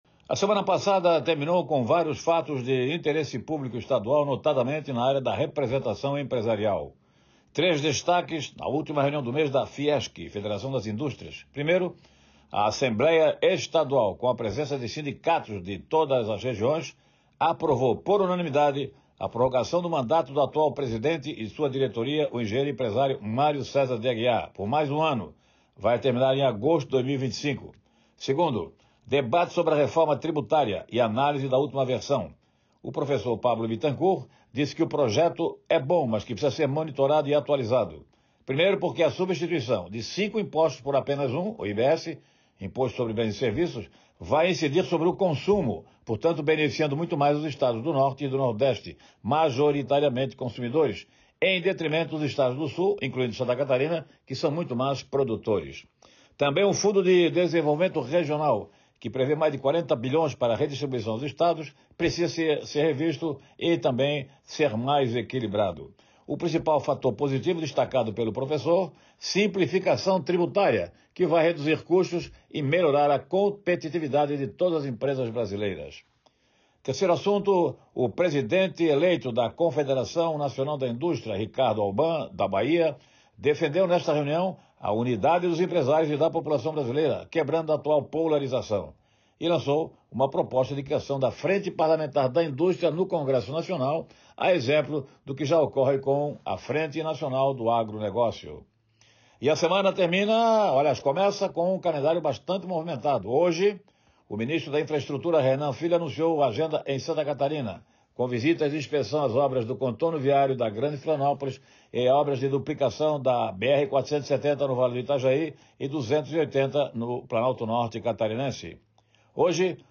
Confira o comentário na íntegra